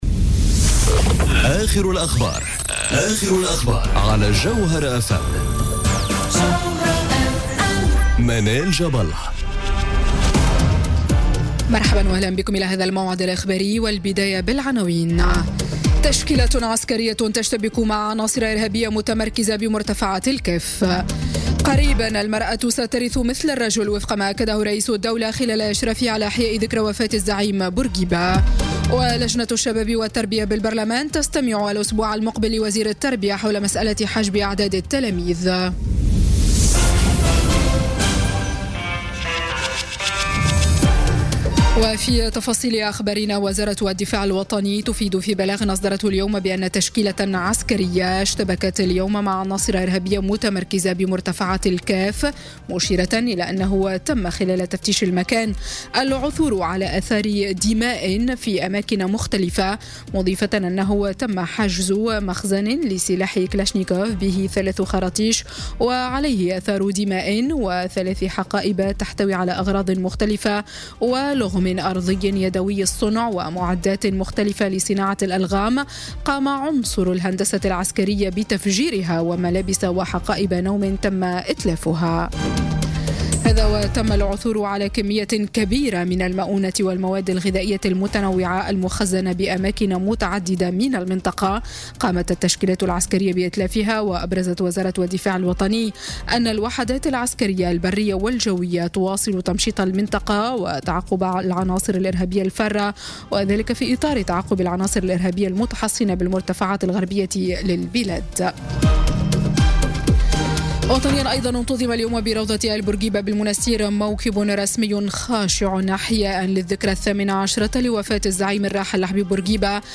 نشرة أخبار السابعة مساءً ليوم الجمعة 6 أفريل 2018